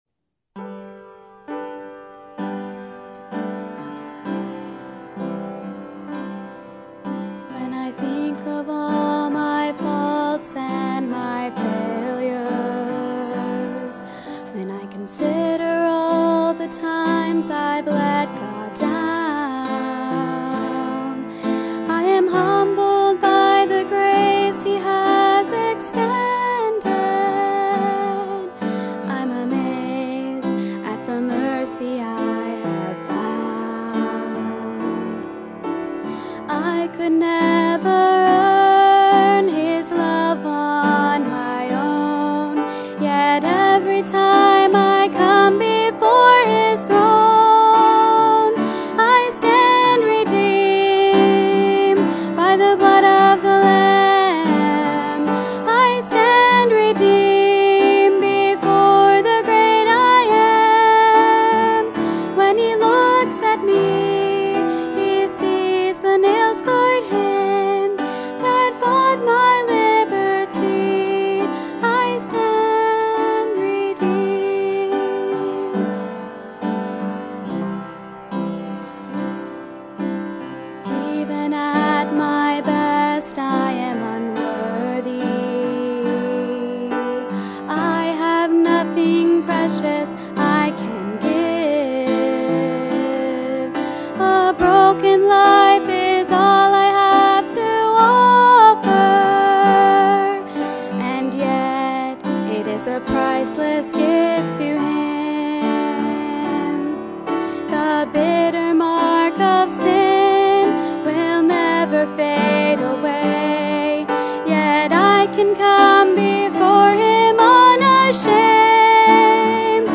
Sermon Date: Wednesday, July 20, 2016 - 7:00pm Sermon Title: Religion or Relationship?